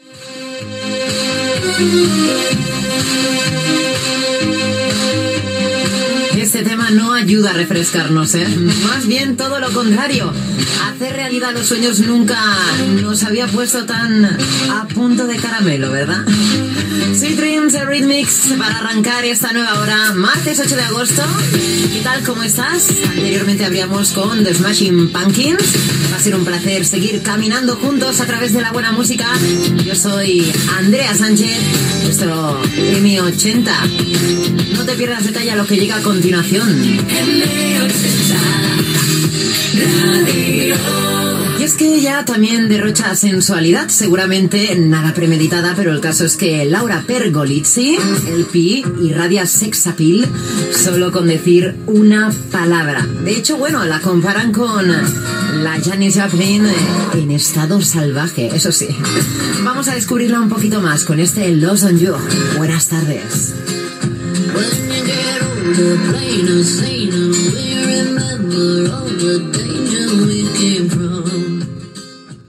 Data, identificació, indicatiu i tema musical Gènere radiofònic Musical